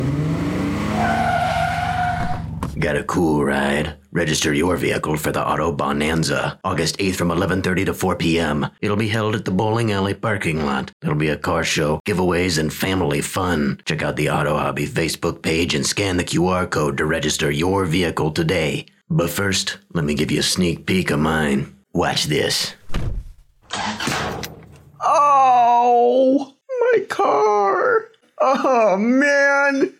This is a 30-second radio spot for the Auto-Bahnanza at Spangdahlem Air Base, Germany, April 16, 2026. The car show will be held Aug. 8, 2026, at the Eifel Lanes Bowling Alley parking lot.